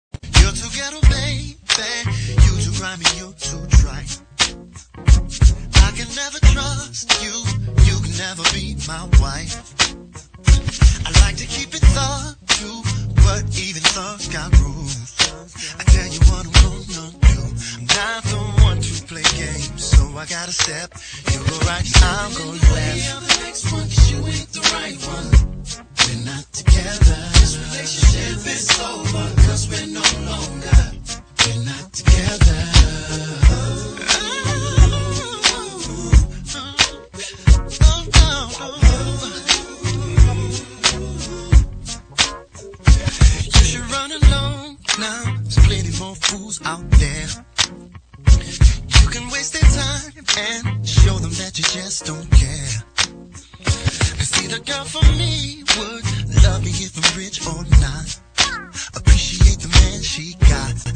Soul/R&B/Jazz